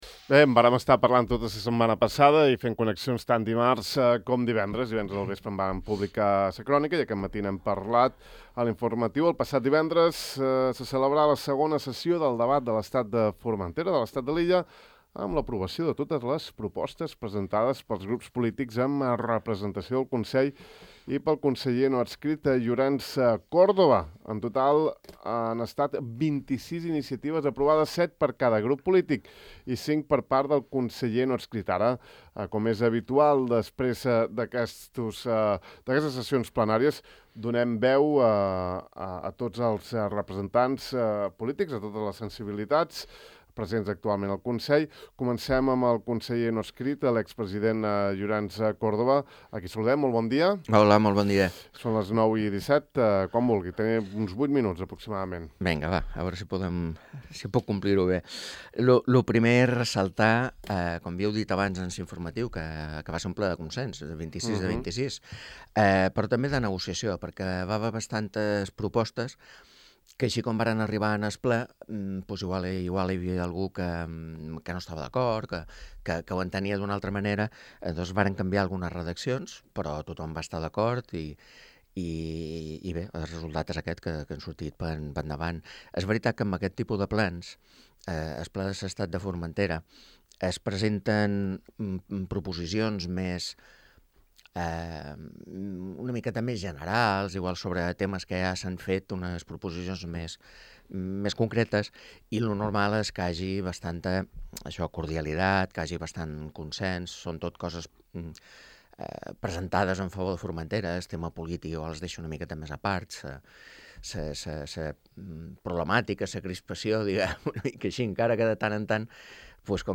Avui dilluns al De far a far, els representants de totes les sensibilitats polítics amb presència al Consell han fet l’habitual valoració post ple del Debat, en aquest cas, de l’estat de Formentera celebrat durant el dimarts i divendres passats. Així, el conseller no adscrit Llorenç Córdoba, la consellera socialista Ana Juan, la de GxF Alejandra Ferrer i el president Òscar Portas han compartit els seus punts de vista sobre aquesta cita política que feia tres anys que no se celebrava.